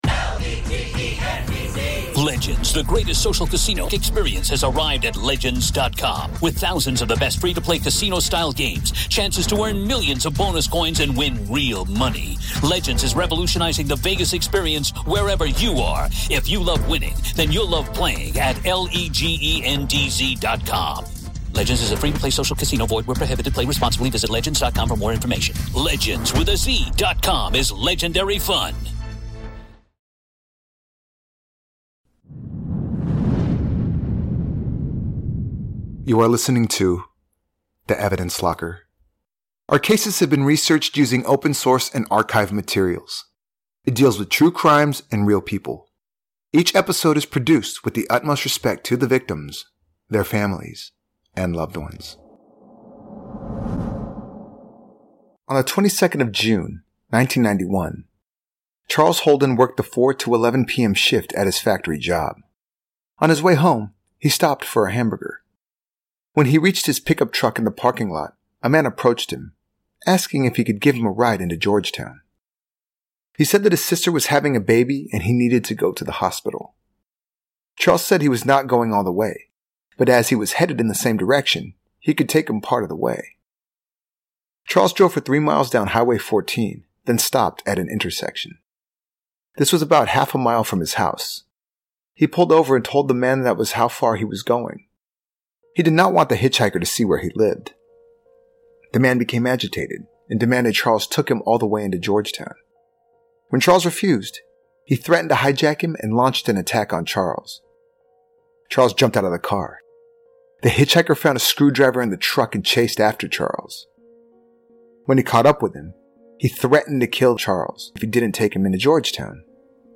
True Crime, Society & Culture, Documentary, History